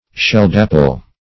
Search Result for " sheldaple" : The Collaborative International Dictionary of English v.0.48: Sheldafle \Sheld"a*fle\, Sheldaple \Sheld"a*ple\, n. [Perhaps for sheld dapple.